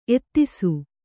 ♪ ettisu